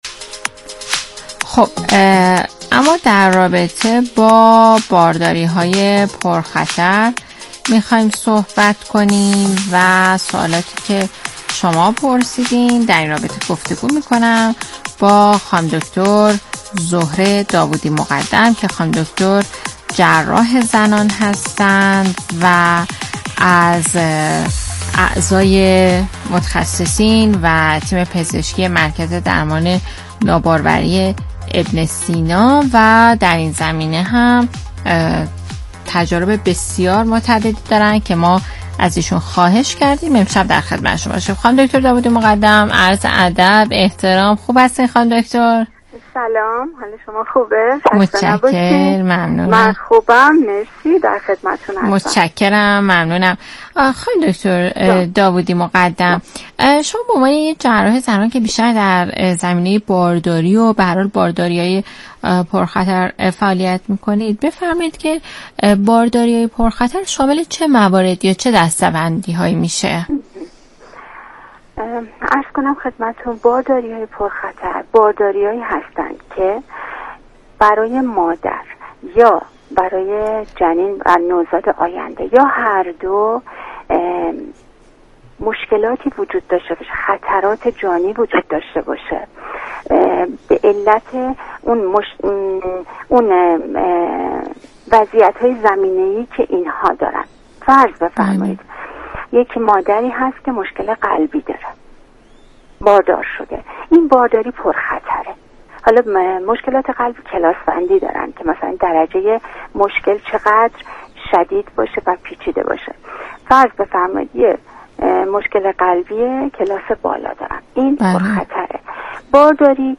مصاحبه رادیویی برنامه سپهر دانش رادیو سلامت